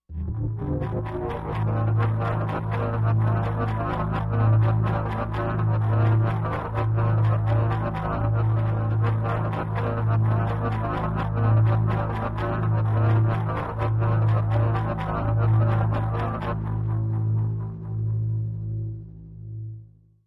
Impulse Replicator, Machine, Low Drone, Mid Pulse, Repeating